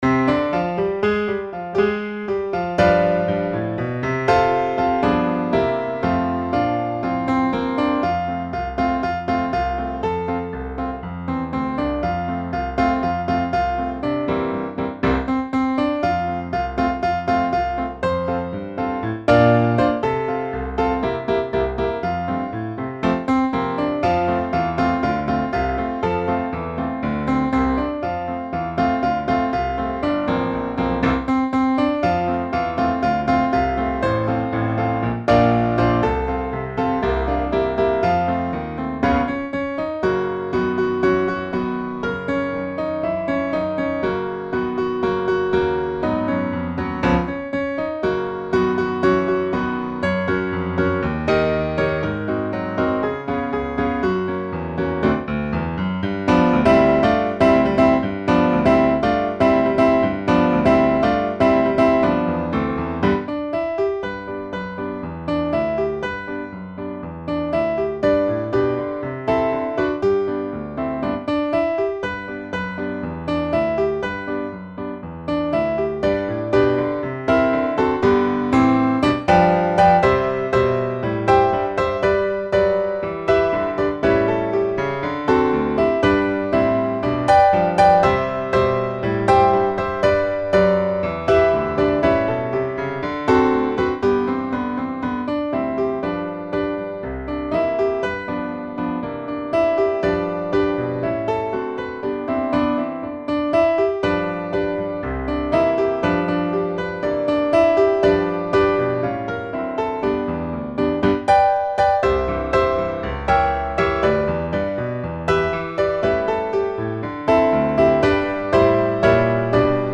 Spirituals for the Intermediate Pianist
Voicing: Piano Collection